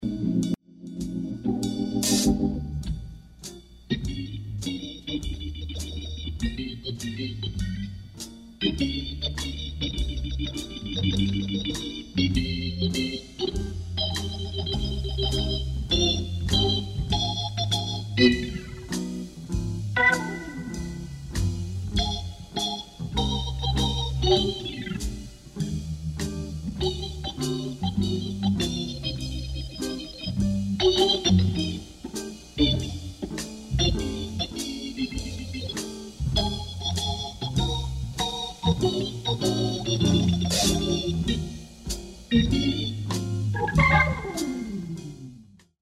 Le 2eme nettement plus groove